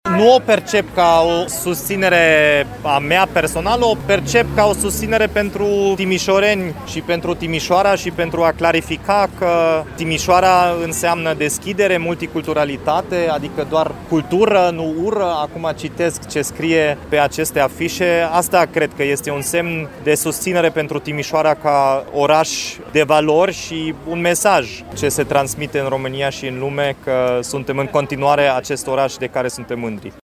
În rândul manifestanților a coborât și primarul Timișoarei, Dominic Fritz.
Dominic-Fritz.mp3